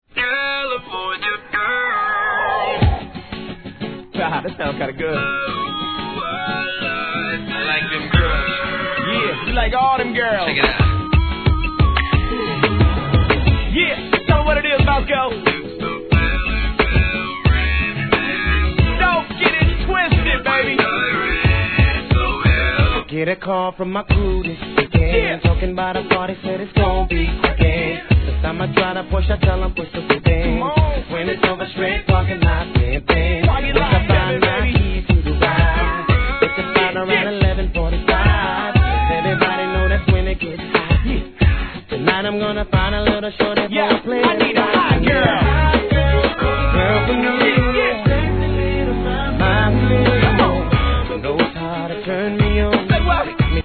HIP HOP/R&B
2001年、出だしからヴォコーダーを用いた人気曲REMIX!!